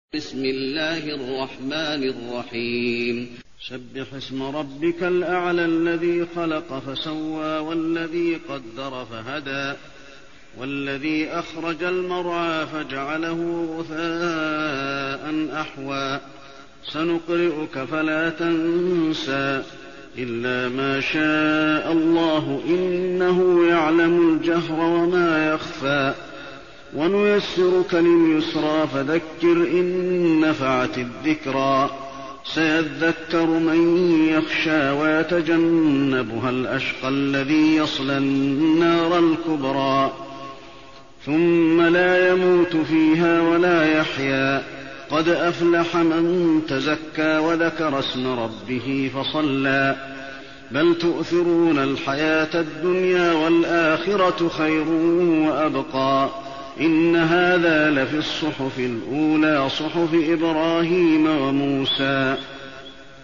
المكان: المسجد النبوي الأعلى